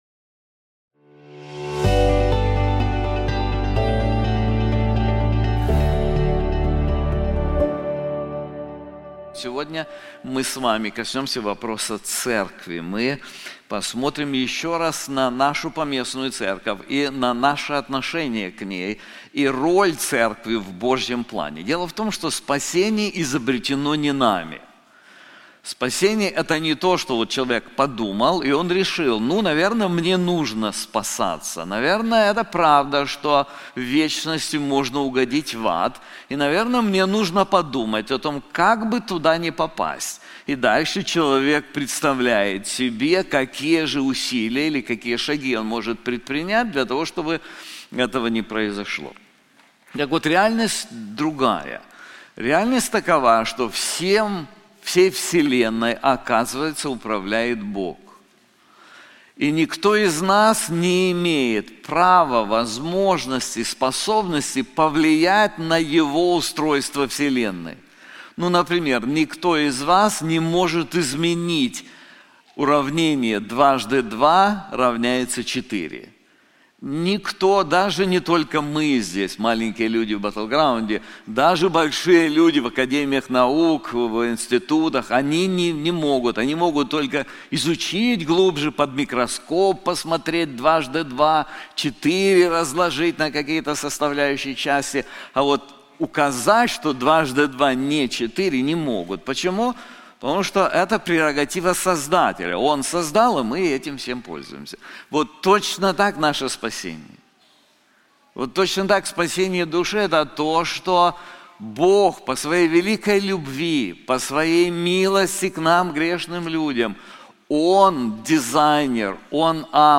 This sermon is also available in English:The Church: Greatness. Blessings. Responsibility • Ephesians 1-5